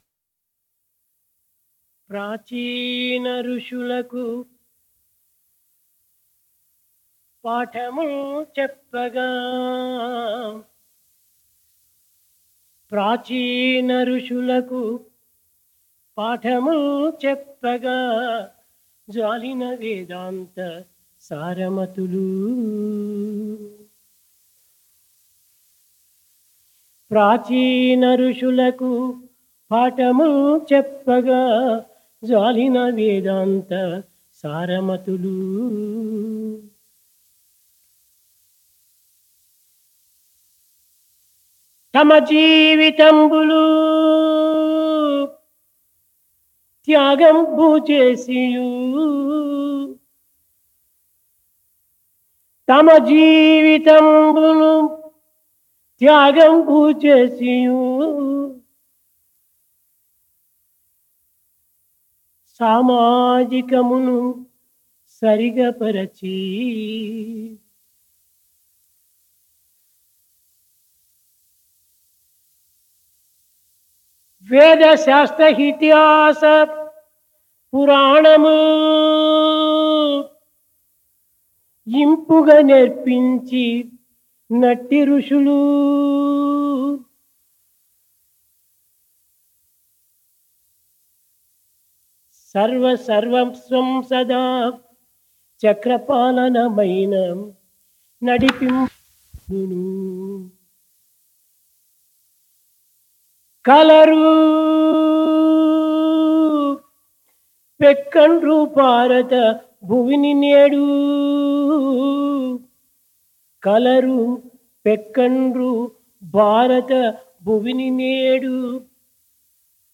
Discourse
Occasion Convocation - 17